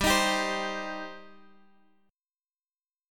Gsus4#5 chord